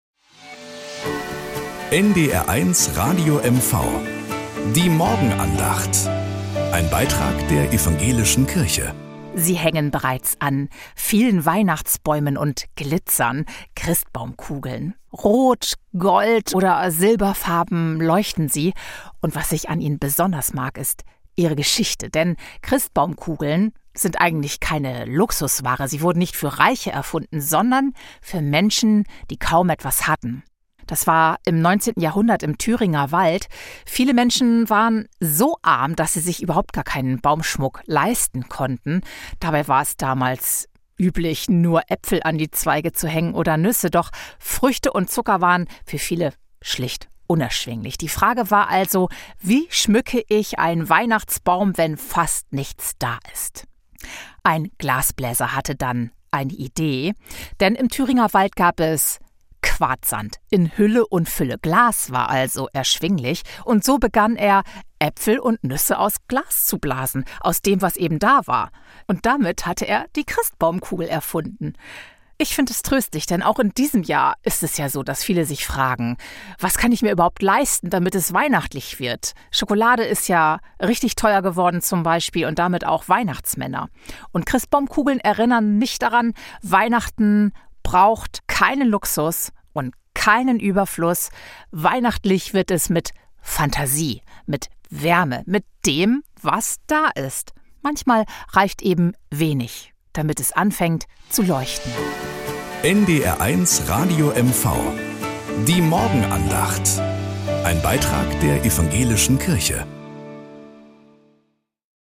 Morgenandacht bei NDR 1 Radio MV
Um 6:20 Uhr gibt es in der Sendung "Der Frühstücksclub" eine